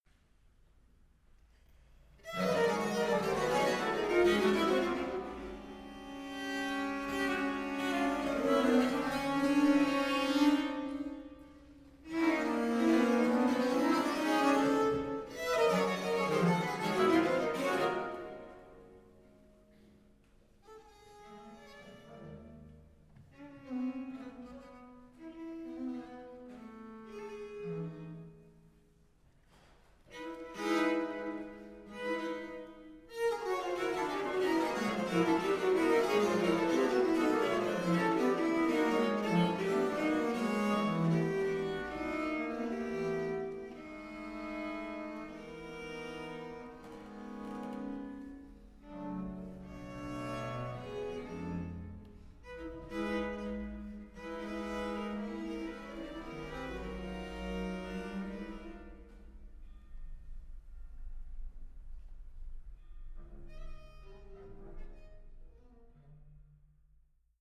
Liveaufnahme